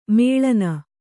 ♪ mēḷana